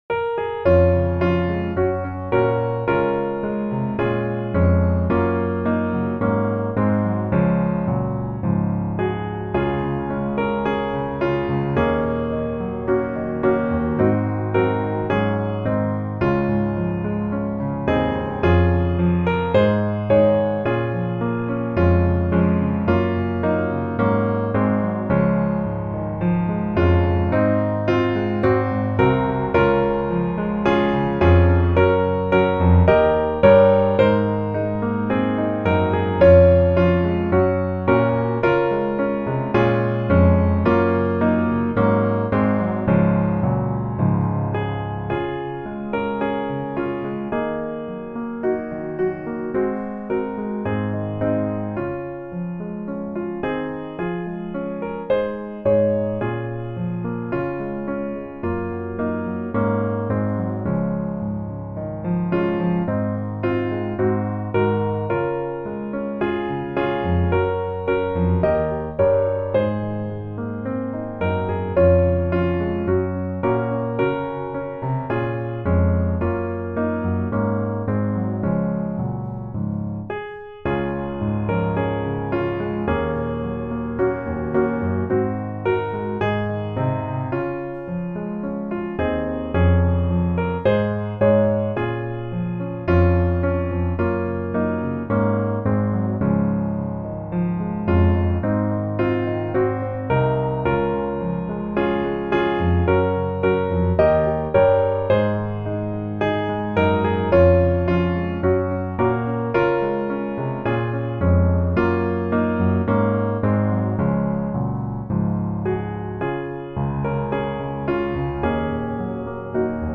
Db Majeur